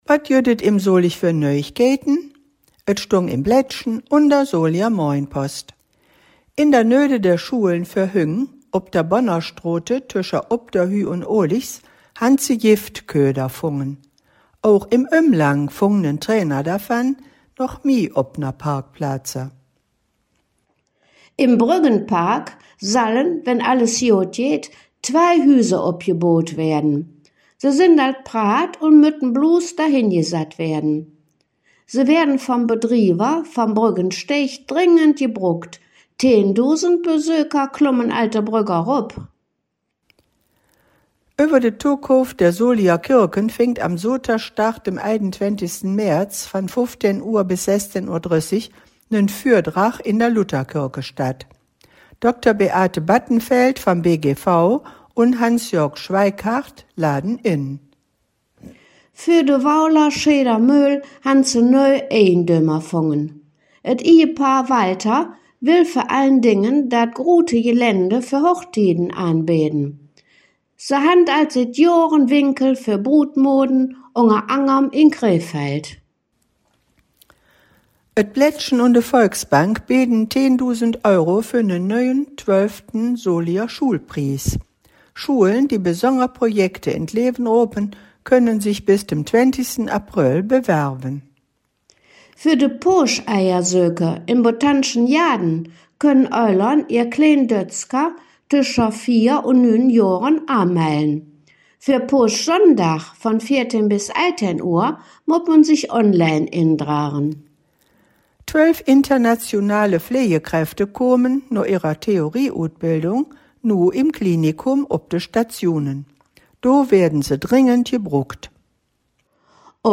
Folge 275 der Nachrichten in Solinger Platt von den Hangkgeschmedden: Themen u.a.: Soliger Kirken, Erdrötsch, nöü Böüm